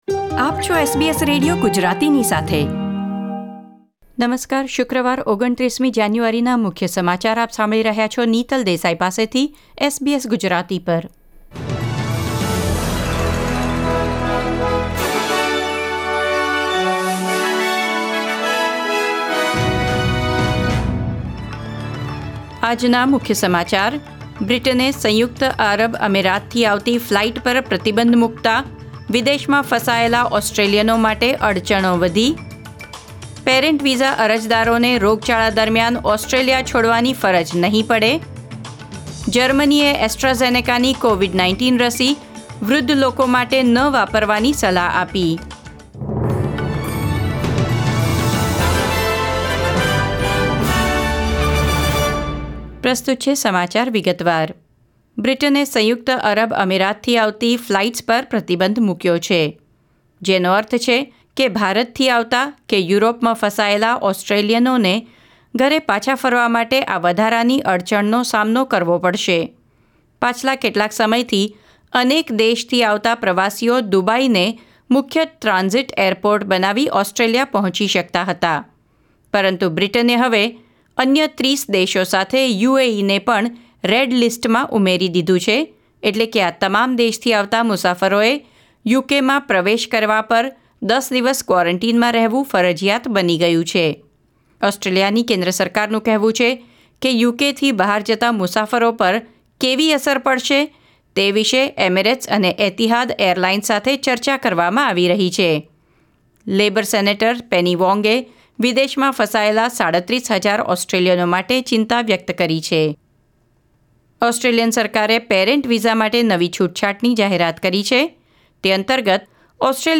SBS Gujarati News Bulletin 29 January 2021